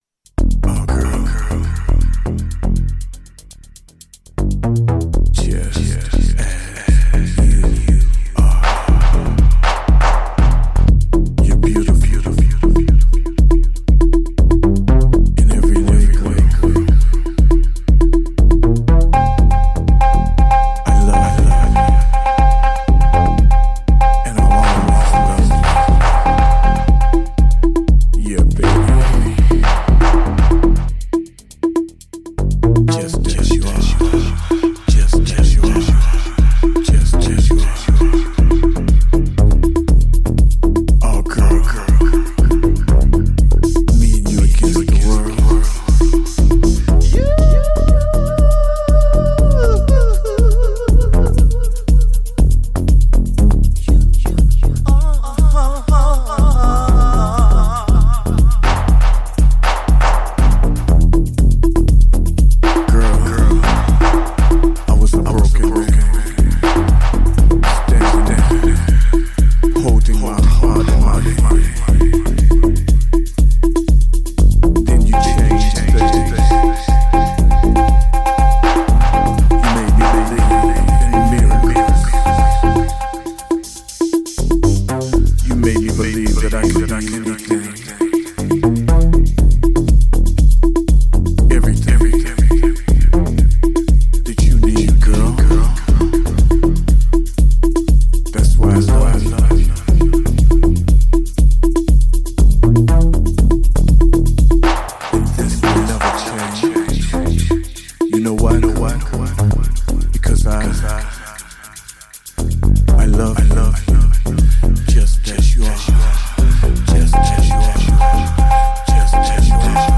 a 90s flavoured, darkside remix of the highest order